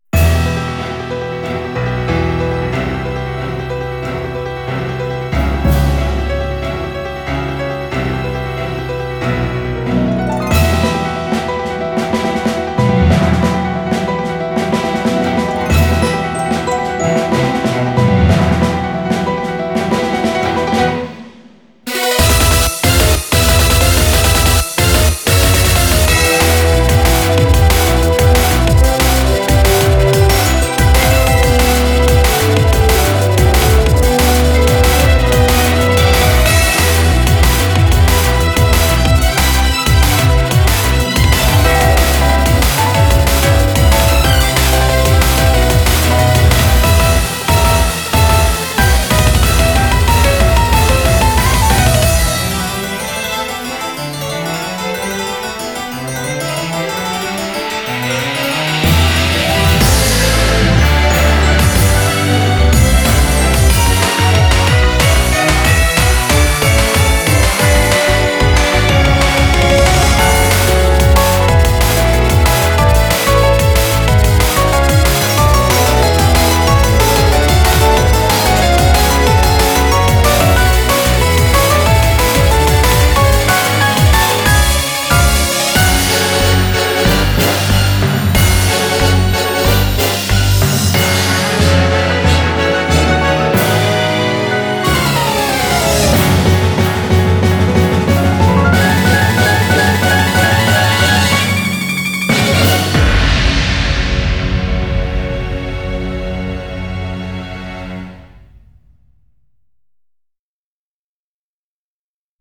BPM185
Audio QualityPerfect (High Quality)
Genre: SYMPHONIC BREAK BEATS